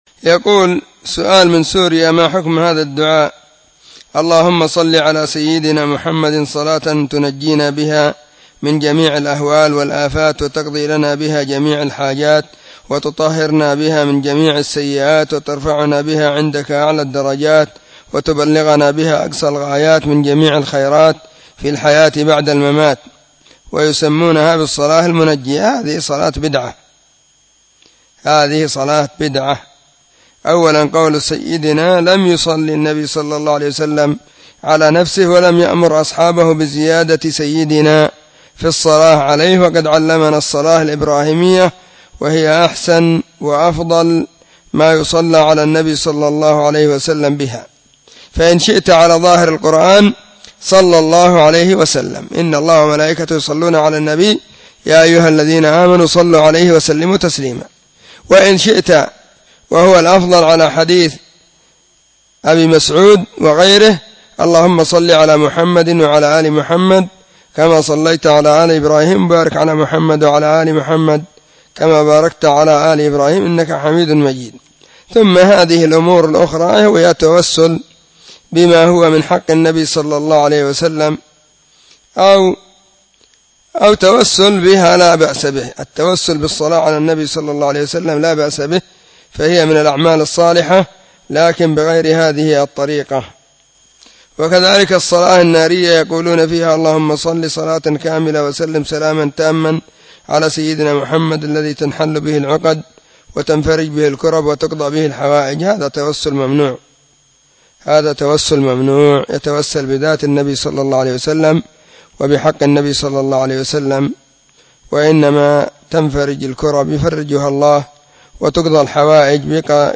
📢 مسجد الصحابة – بالغيضة – المهرة، اليمن حرسها الله.
🔸🔹 سلسلة الفتاوى الصوتية المفردة 🔸🔹